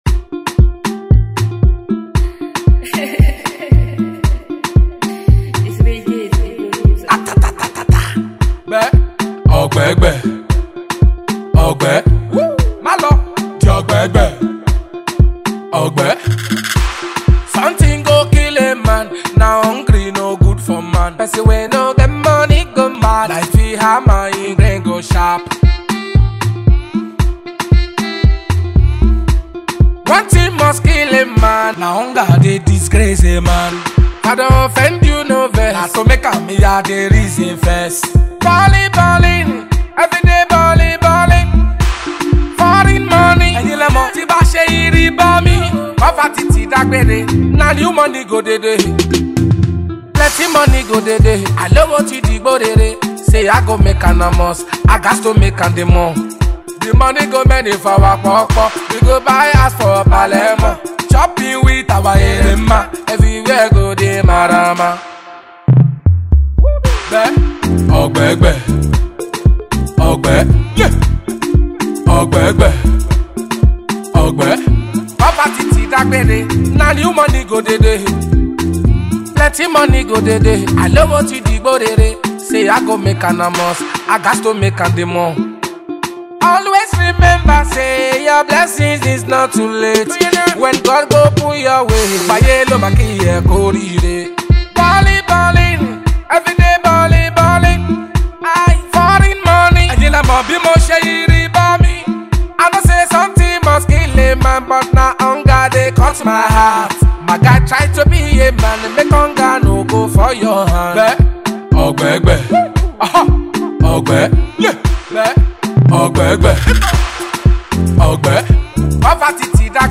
A new dance hall jamz